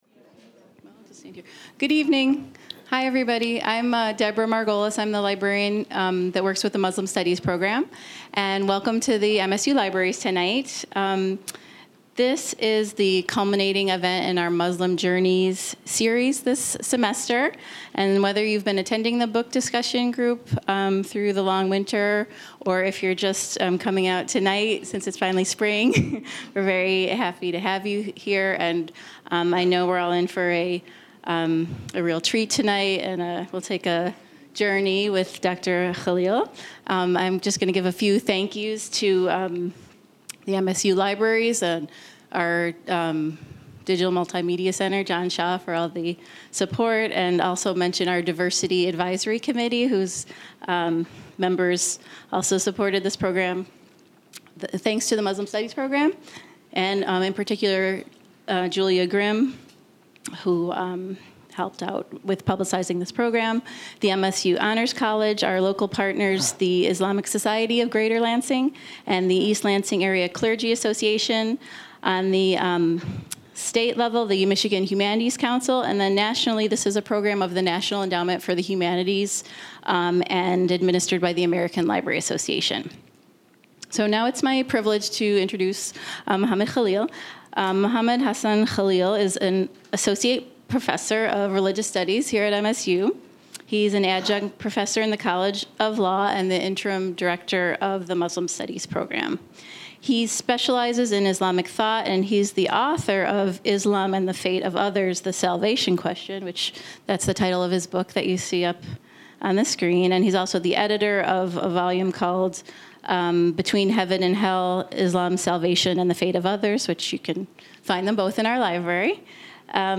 Held at the MSU Main Library.